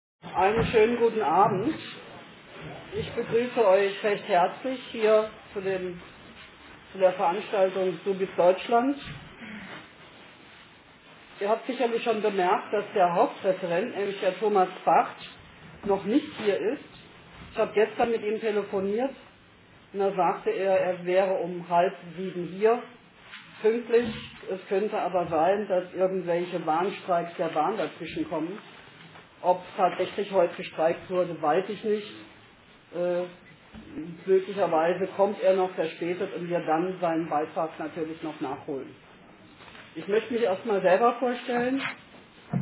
Attac-AG "Privatisierung Nein!", GEW BV-Ffm, ver.di FB 5 (Ffm), Jusos Ffm: hatten ins Gewerkschaftshaus eingeladen.
Über 80 Besucher erlebten einen interessanten Abend.